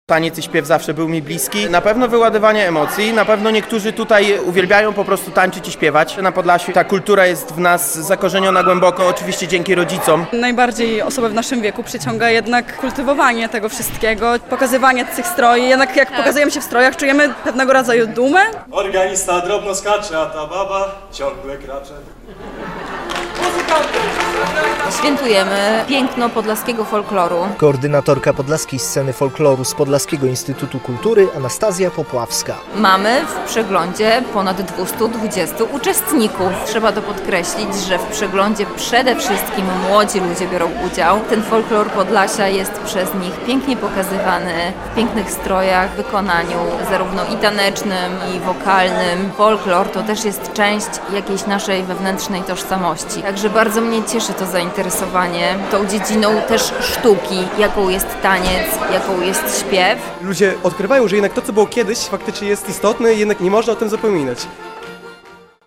Prezentują barwne stroje, grają na tradycyjnych instrumentach i pokazują, jak dawniej bawili się mieszkańcy Podlasia, Suwalszczyzny czy Kurpiowszczyzny.
W Łapach ponad 200 tancerzy i muzyków z naszego regionu, ale nie tylko bierze udział w Podlaskiej Scenie Folkloru, czyli Ogólnopolskim Przeglądzie Folklorystycznym.